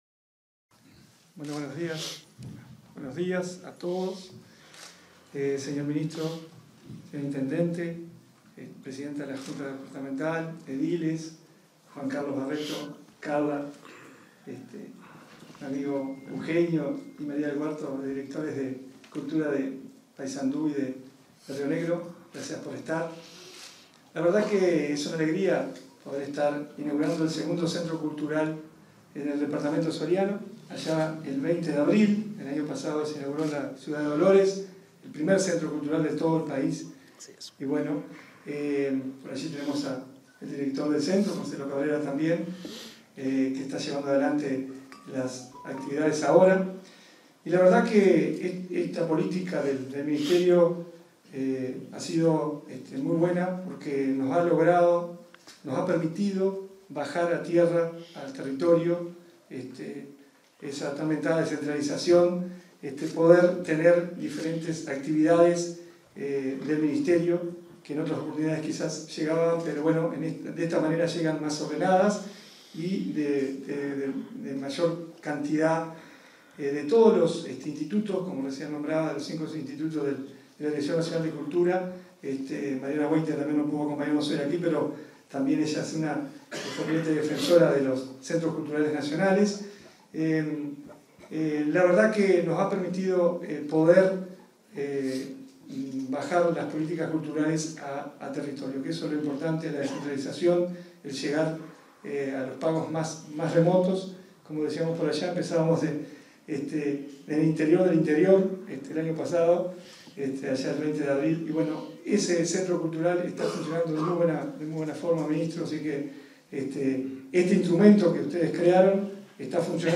Ceremonia de nominación de la Casa de la Cultura de Mercedes como Centro Cultural Nacional 17/05/2023 Compartir Facebook X Copiar enlace WhatsApp LinkedIn En el marco de la ceremonia de nominación de la Casa de la Cultura de Mercedes como Centro Cultural Nacional, este 17 de mayo, se expresaron director de Cultura de Soriano, Javier Utermark; el intendente departamental, Guillermo Besozzi, y el ministro de Educación y Cultura, Pablo da Silveira.